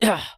CK受伤4.wav
CK受伤4.wav 0:00.00 0:00.39 CK受伤4.wav WAV · 33 KB · 單聲道 (1ch) 下载文件 本站所有音效均采用 CC0 授权 ，可免费用于商业与个人项目，无需署名。
人声采集素材/男2刺客型/CK受伤4.wav